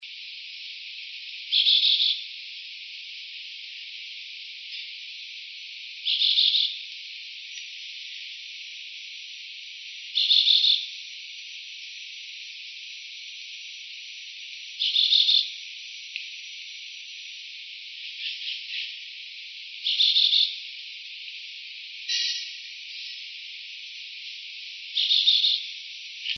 Chestnut-bellied Euphonia (Euphonia pectoralis)
Location or protected area: Bio Reserva Karadya
Condition: Wild
Certainty: Recorded vocal